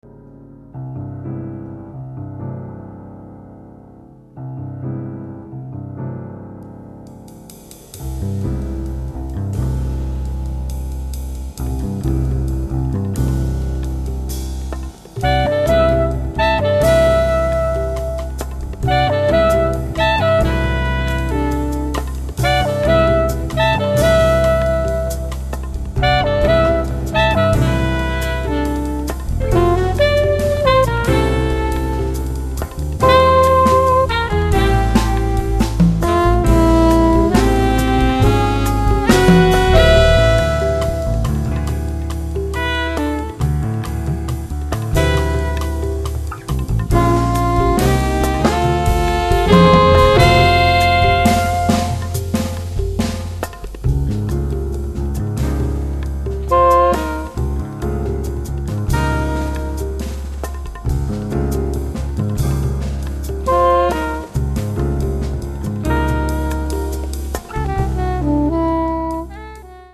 sax soprano, flauto, flauto in sol
tromba